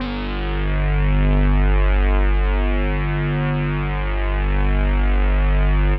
G1_trance_lead_2.wav